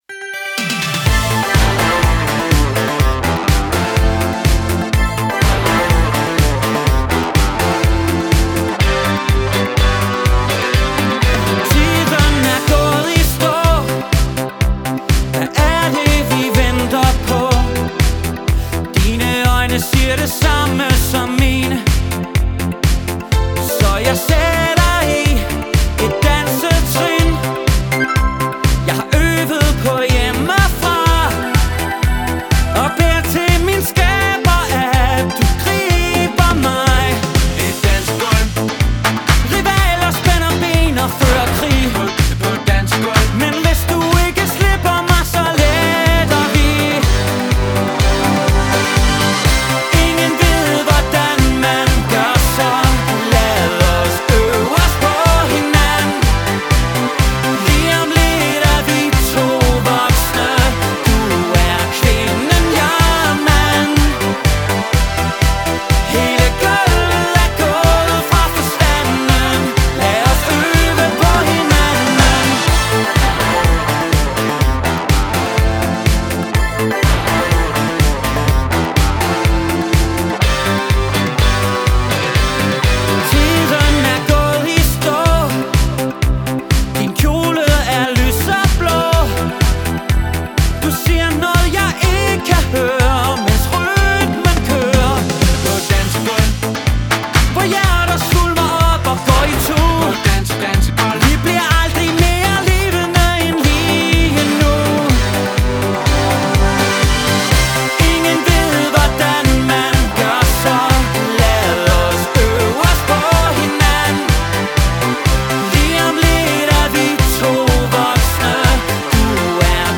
это энергичная поп-песня